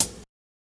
HIHAT GRITTY I.wav